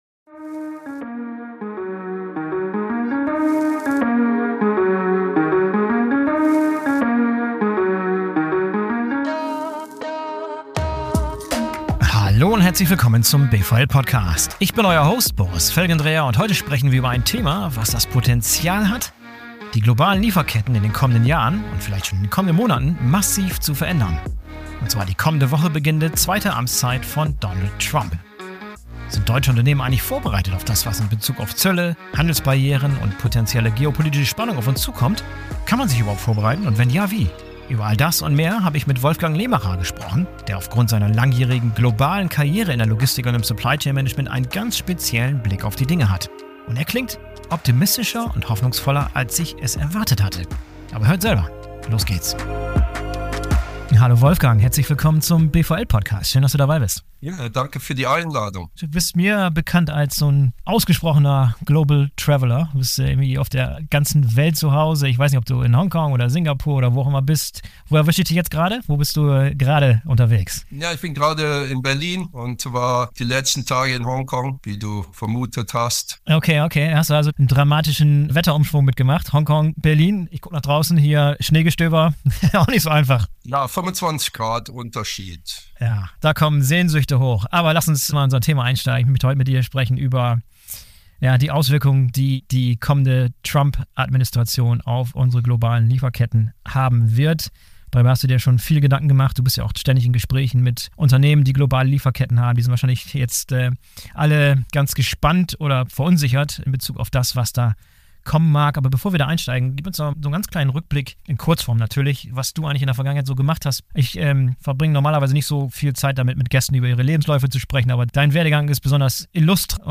In einem ausführlichen, persönlichen Gespräch, das einen echten Blick hinter die Kulissen der Unternehmen, der Personen und deren Ideen und Herausforderungen ermöglicht.